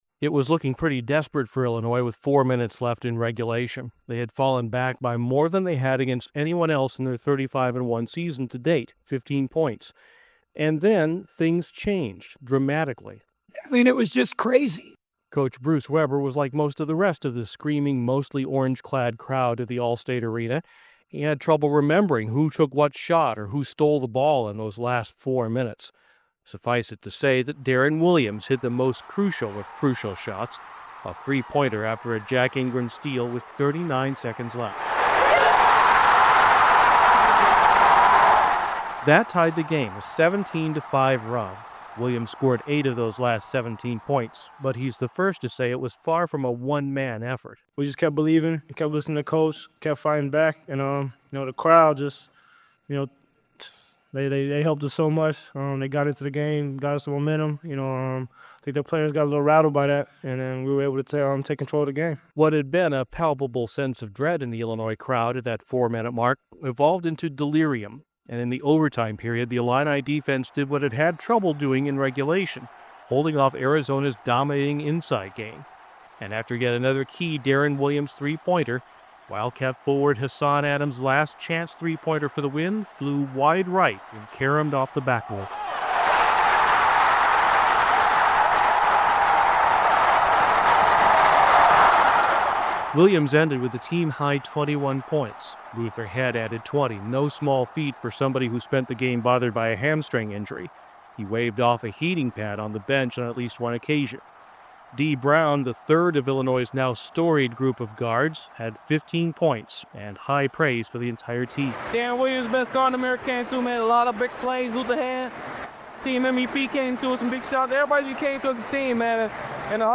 News Local/State